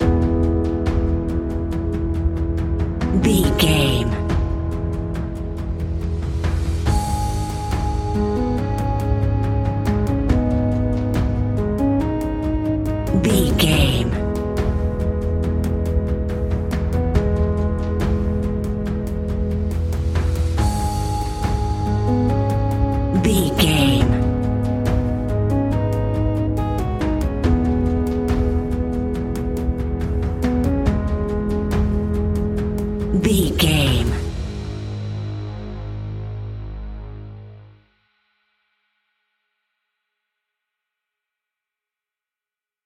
royalty free music
In-crescendo
Thriller
Aeolian/Minor
G#
ominous
dark
eerie
synthesiser
drums
instrumentals
horror music